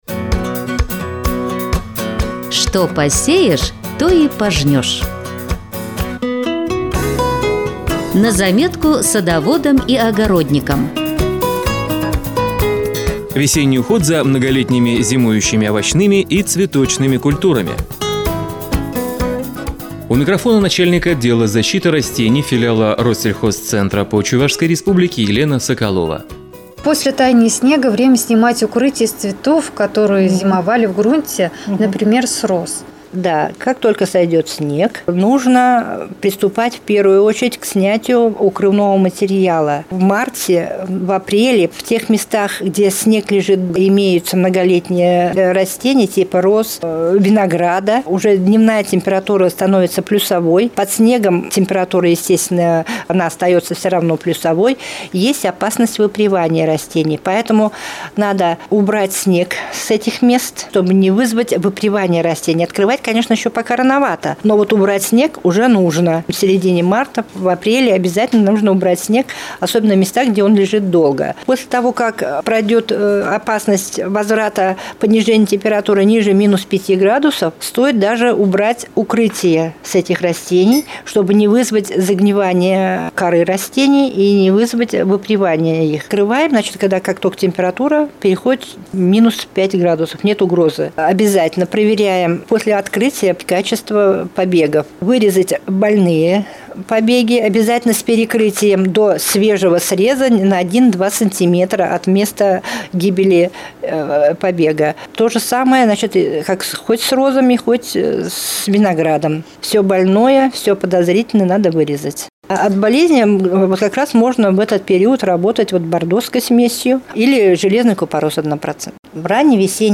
Выступление
Первоисточник: ГТРК "Чувашия" - Радио России - Чувашия